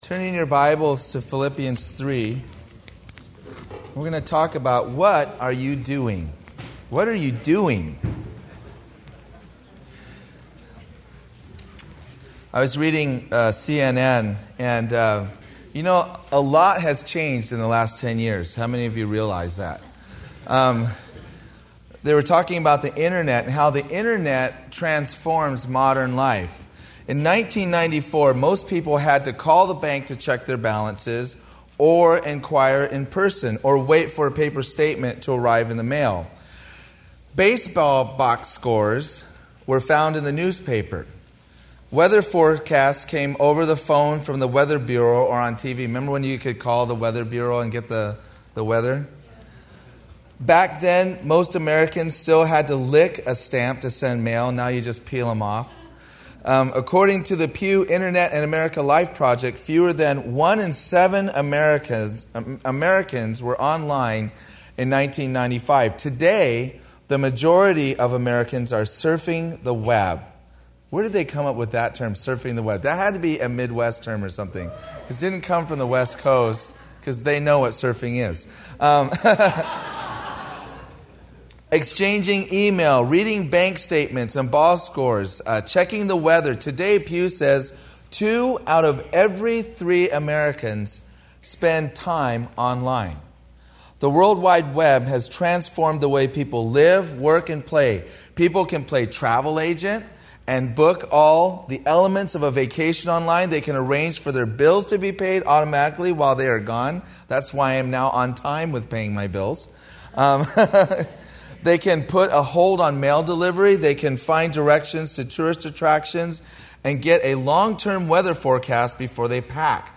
Series: 2005 Northeast Coast Calvary Chapel Worship Conference
Campus: Calvary Chapel Westside